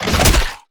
Sfx_creature_rockpuncher_chase_os_01.ogg